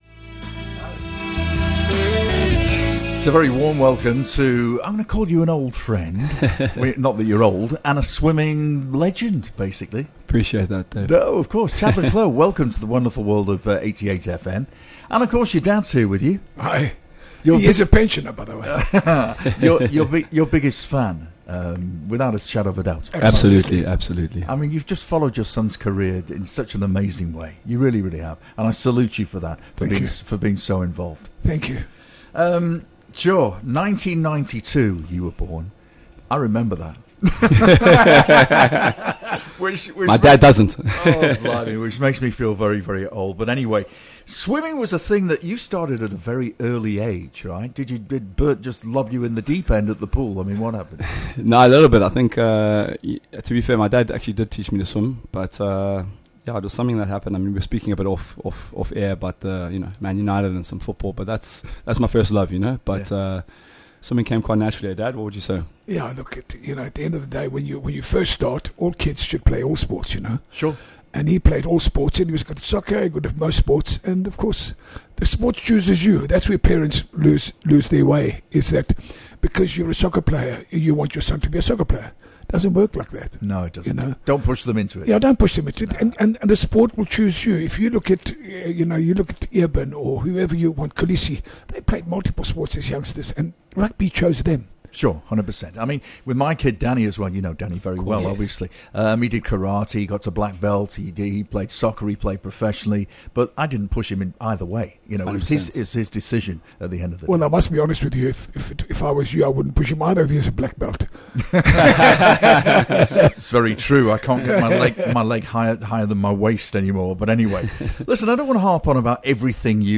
Broadcasting live from the heart of Ballito, The Afternoon Show serves up a curated mix of contemporary music and hits from across the decades, alongside interviews with tastemakers and influencers, and a healthy dose of local news & views from the booming KZN North Coast.